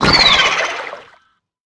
Sfx_creature_penguin_flinch_sea_03.ogg